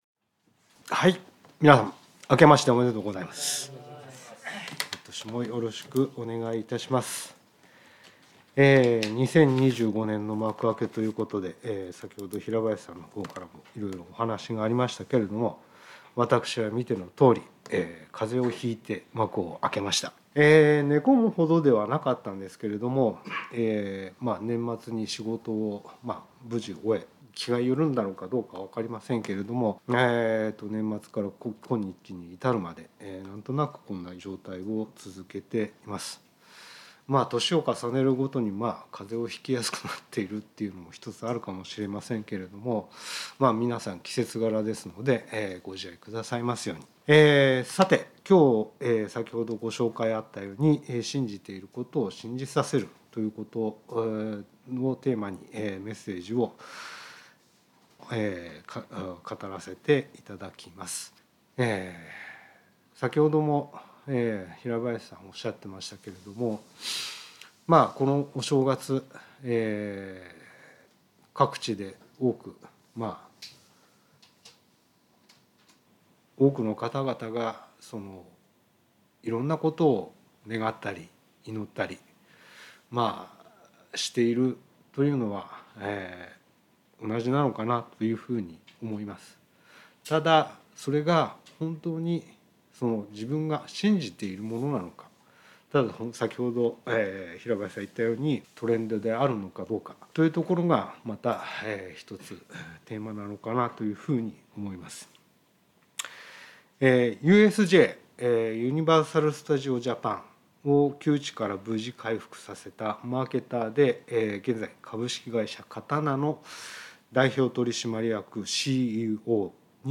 聖書メッセージ No.249